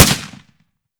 7Mag Bolt Action Rifle - Gunshot B 003.wav